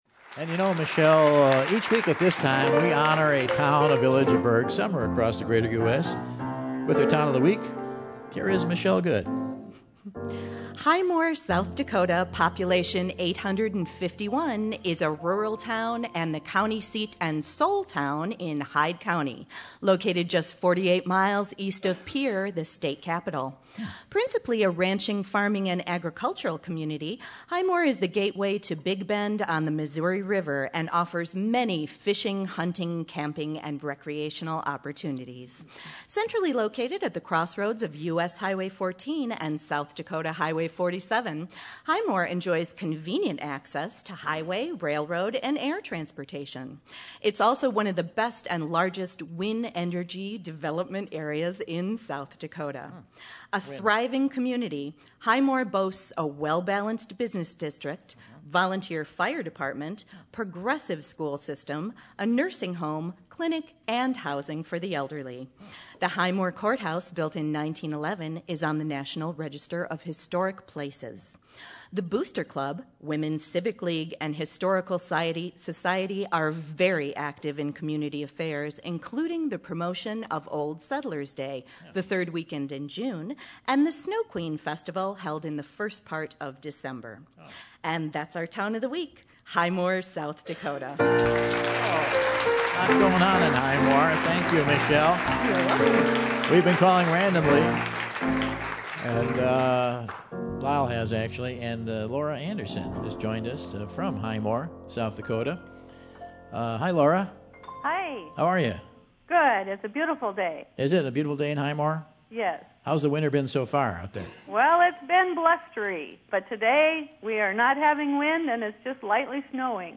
Guest announcer